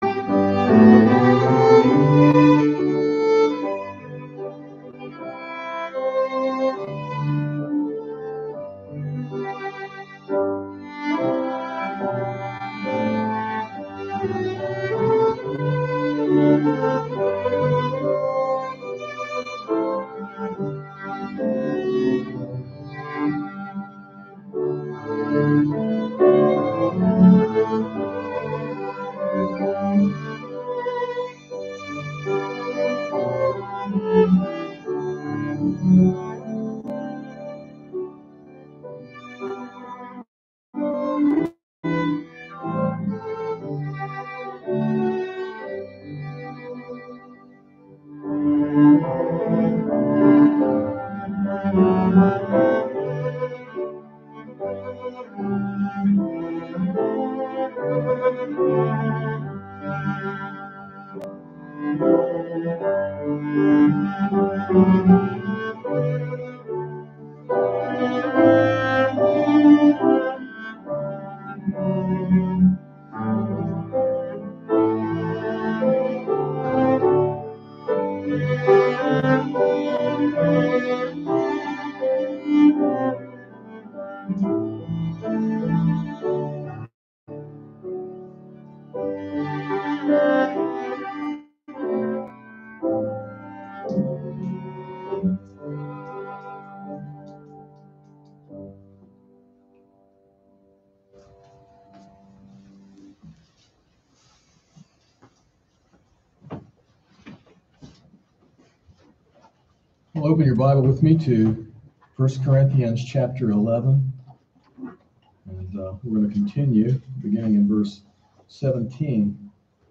sermon-11-7-21.mp3